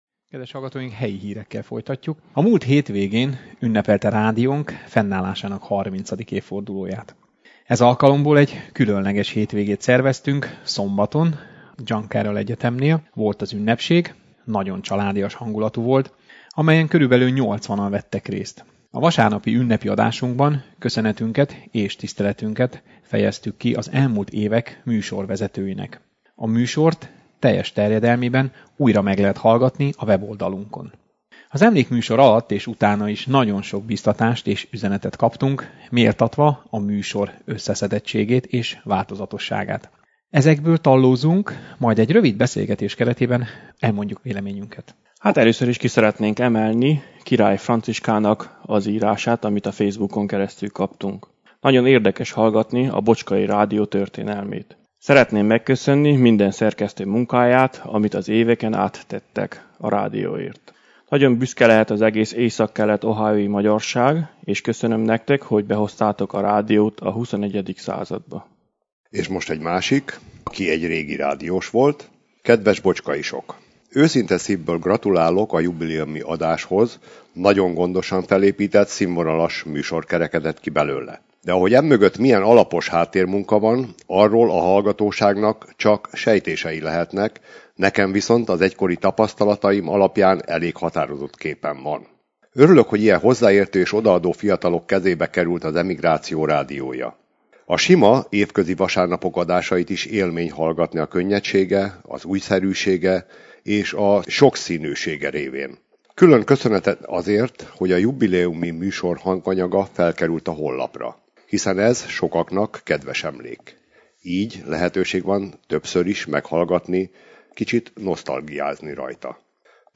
Ezekből tallóztunk majd egy rövid beszélgetés kereteben elmondjuk a véleményünket.